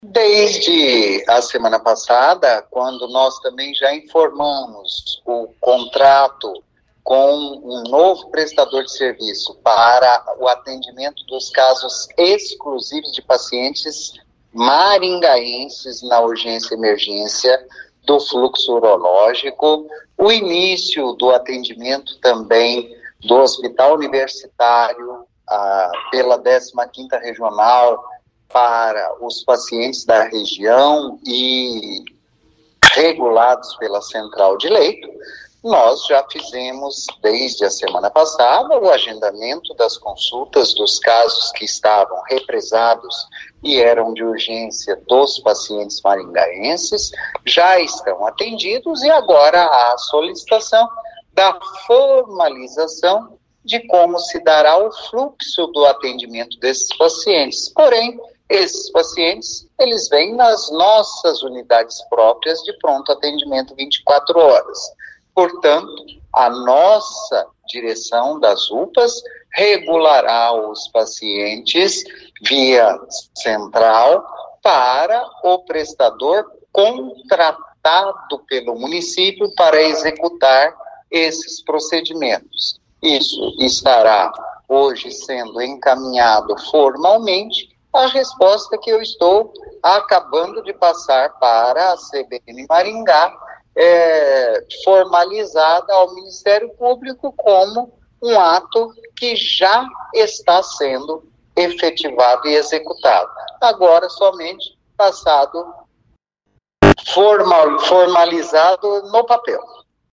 O secretário de Saúde Antônio Carlos Nardi diz que os documentos da formalização do contrato entre o Município e o prestador de serviço serão entregues nesta quinta-feira (18) à Justiça e que os pacientes atendidos por este novo prestador são apenas de Maringá.